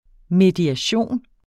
Udtale [ mediaˈɕoˀn ]